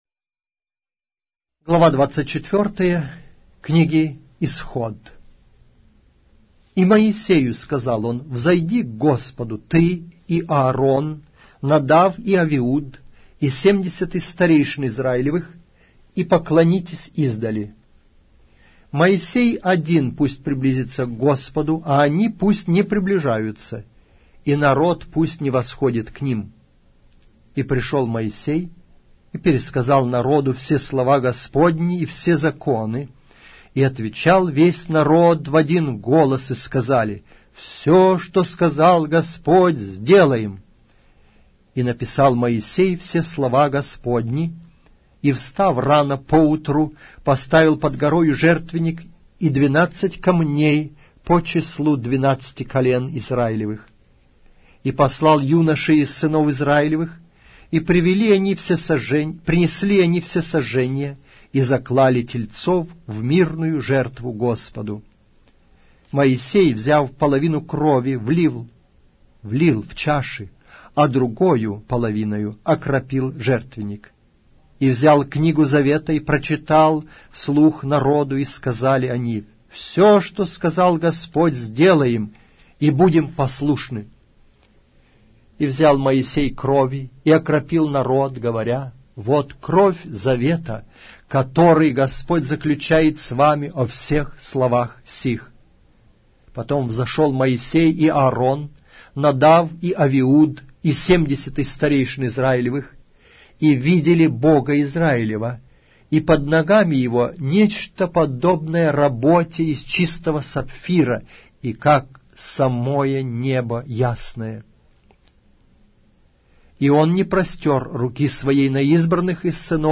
Аудиокнига: Книга 2-я Моисея. Исход